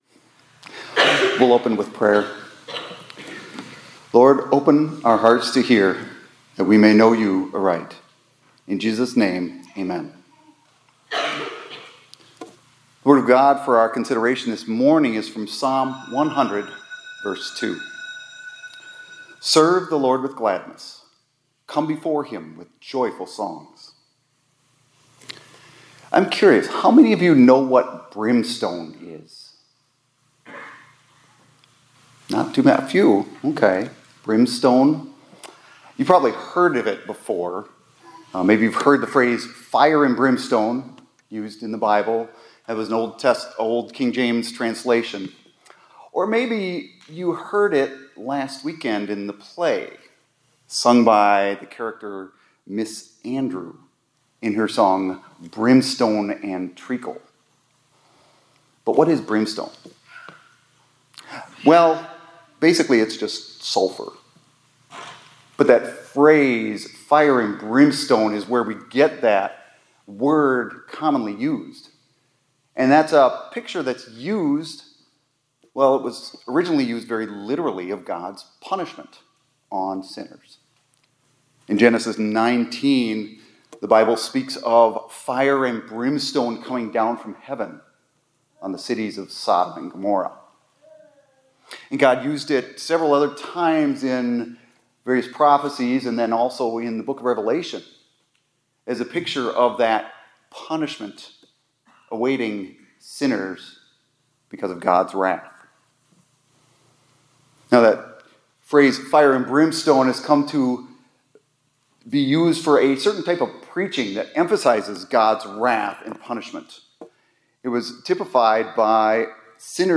2024-11-22 ILC Chapel — No More Brimstone